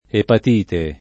[ epat & te ]